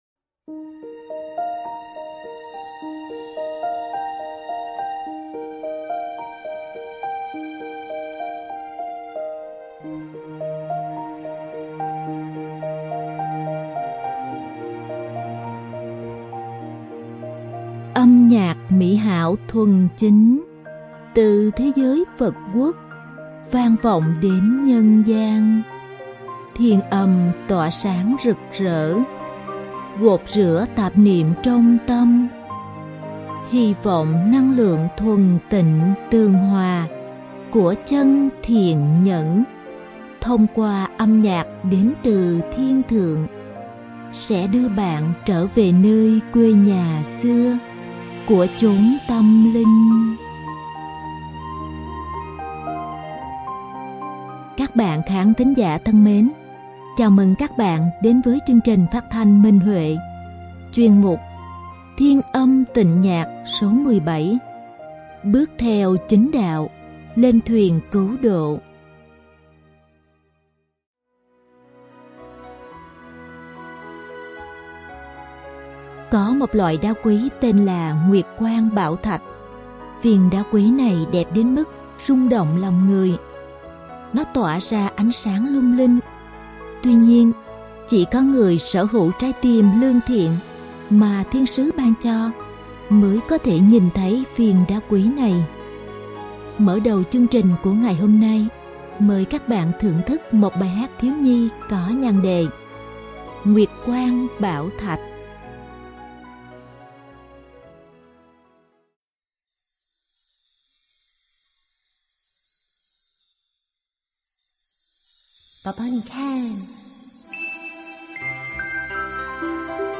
Bài hát thiếu nhi
Màn độc tấu sáo trúc